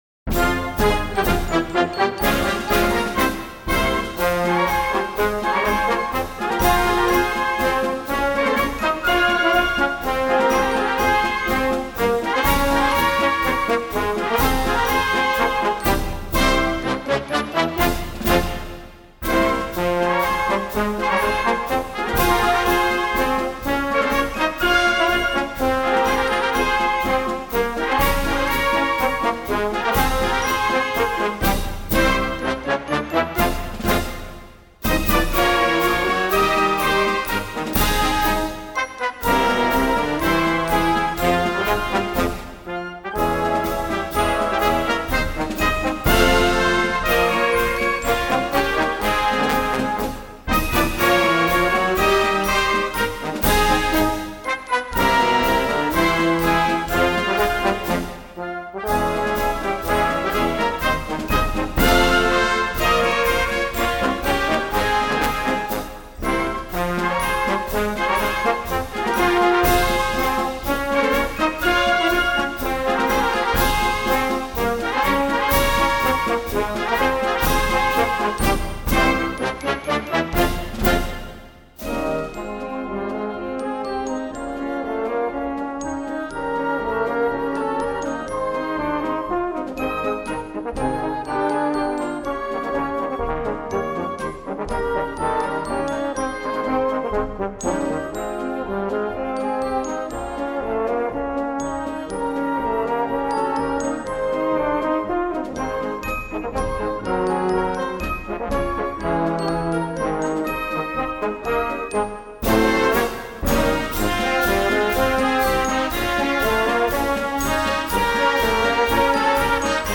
Voicing: Quickstep